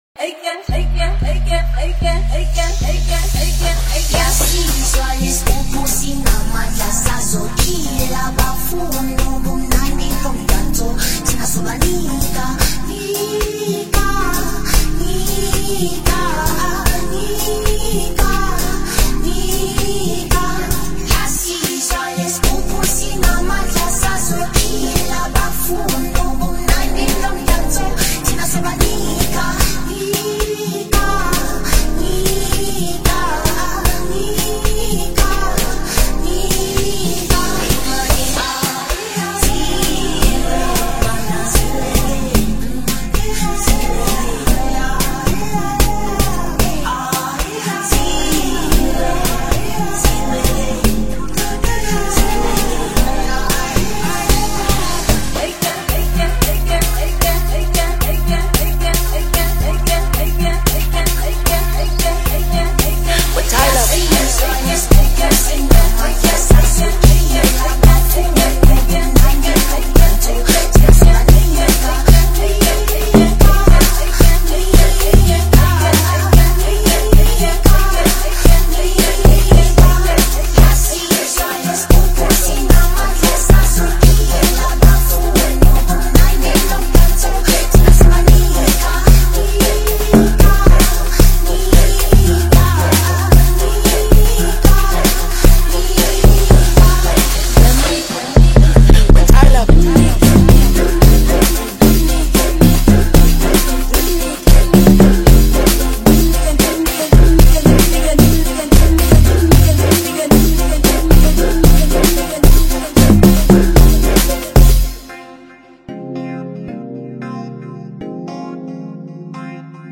Descarger torrent datafilehost Amapiano